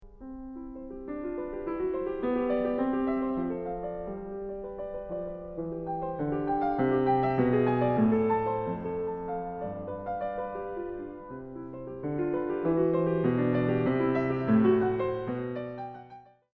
The third, and last variation, continues with four notes on the beat:
The two hands plays out like a duet but the music moves at an even more restless pace now.